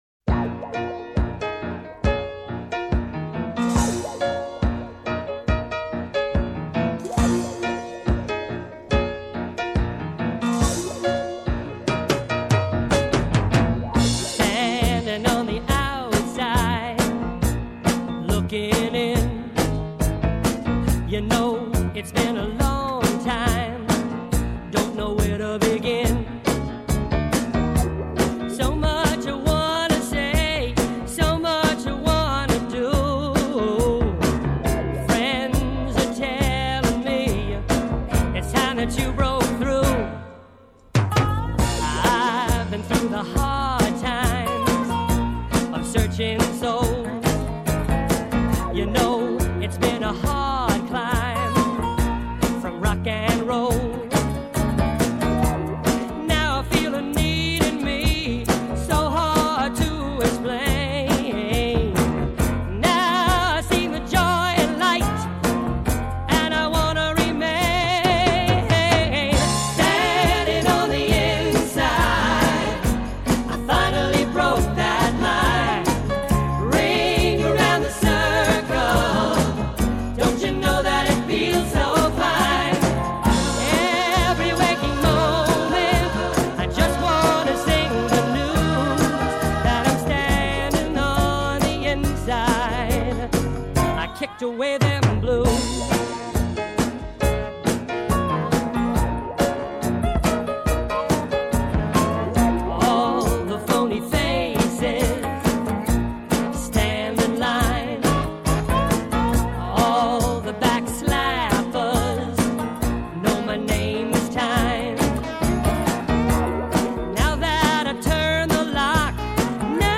Genre: Rock.